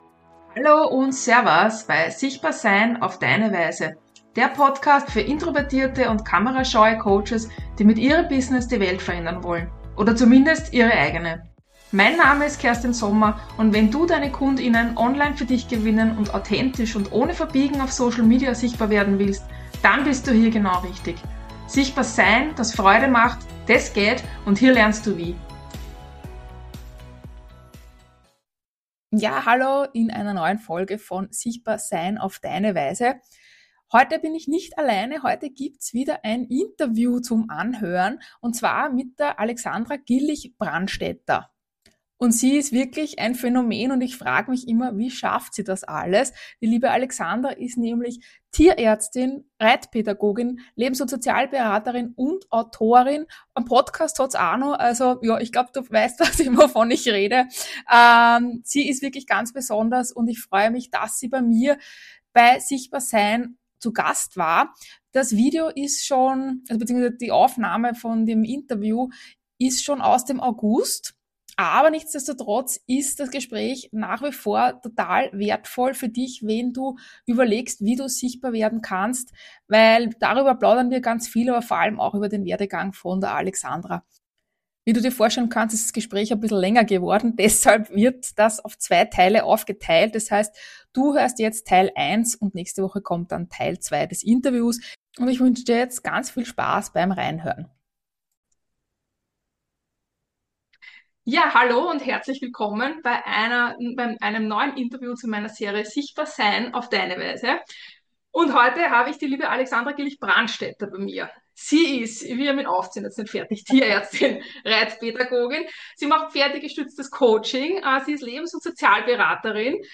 Sichtbar:SEIN - auf deine Weise: Interviews mit tollen Frauen, die über ihren Weg in die Sichtbarkeit und Selbstständigkeit sprechen.